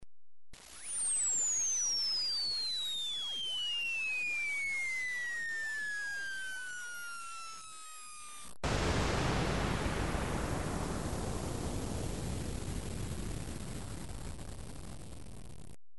دانلود صدای بمب و موشک 12 از ساعد نیوز با لینک مستقیم و کیفیت بالا
جلوه های صوتی